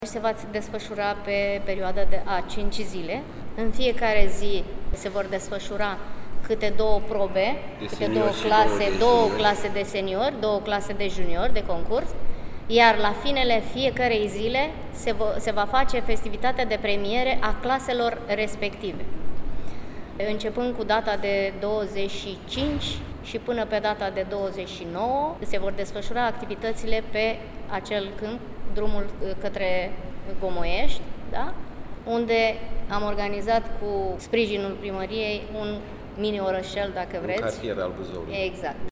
În cadrul unei conferințe de presă organizată la Primăria Buzău